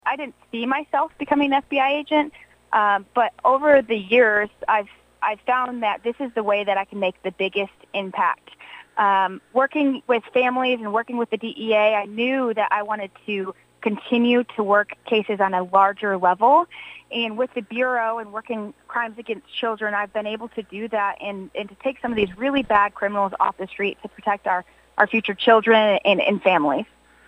the Cromwell News Team held a phone interview